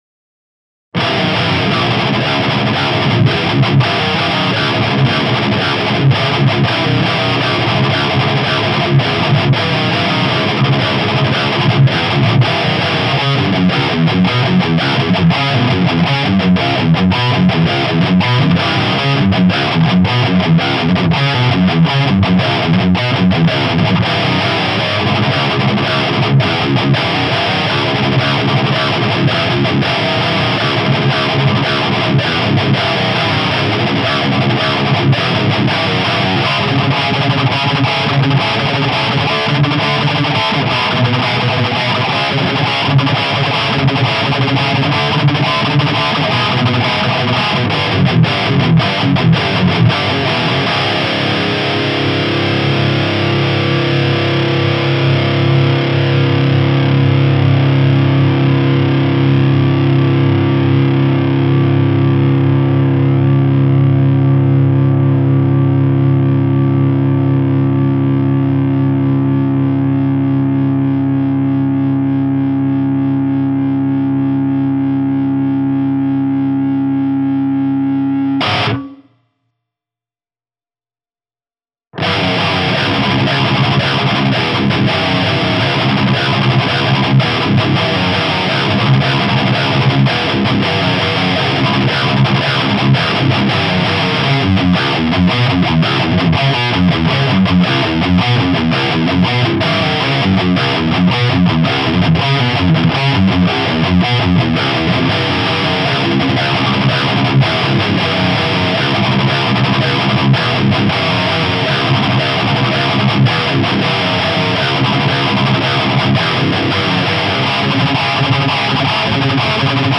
Guess the Pickup Tone Test. Painkiller vs Stock Vantage pickup.